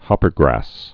(hŏpər-grăs)